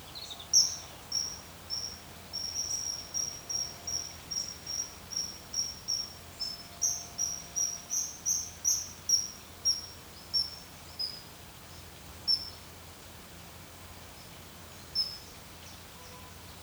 Picapeixe
Martín pescador alcedo atthis
Canto
Ademais, emite un característico chamado agudo, un “tsiip” que se escoita con frecuencia cando voa ou se comunica coa súa parella.